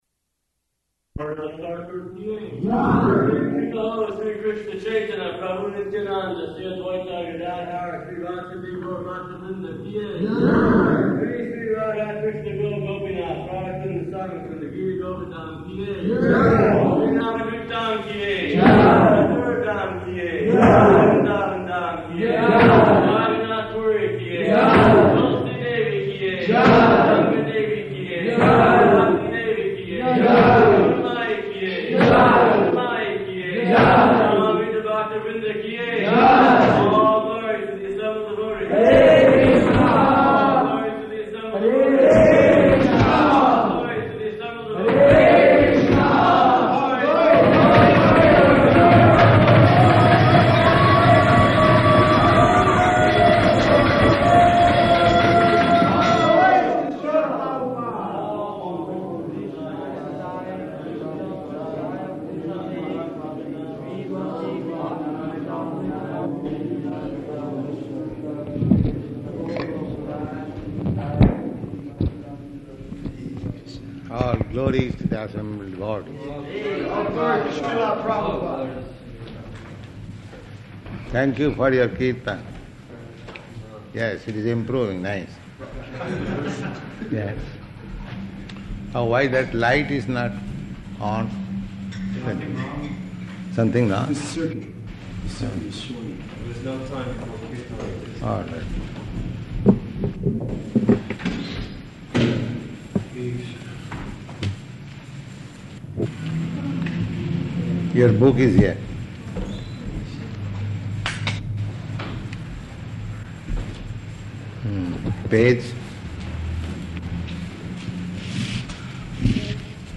Bhagavad-gītā 4.31 --:-- --:-- Type: Bhagavad-gita Dated: January 11th 1969 Location: Los Angeles Audio file: 690111BG-LOS_ANGELES.mp3 Devotee: [chants prema dvani ] Prabhupāda: All glories to the assembled devotees.